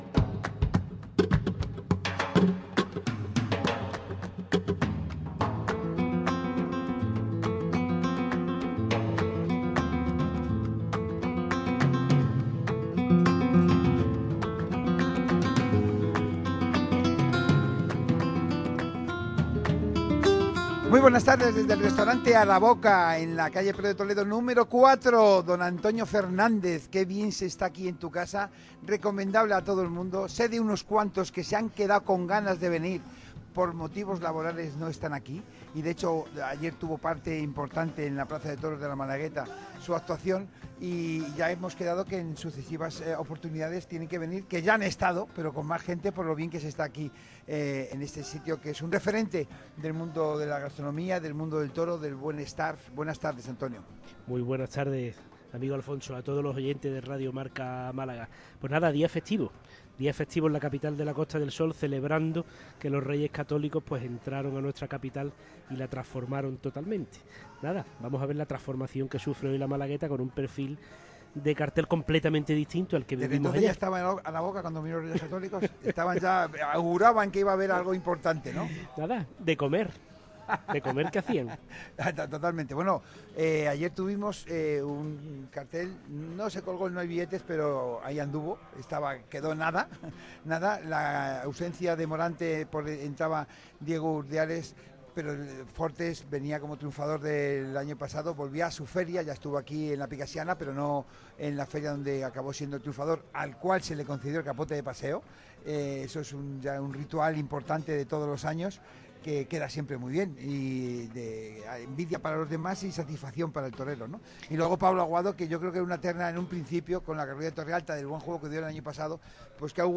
El podcast de la tertulia del martes 19 de agosto de 2025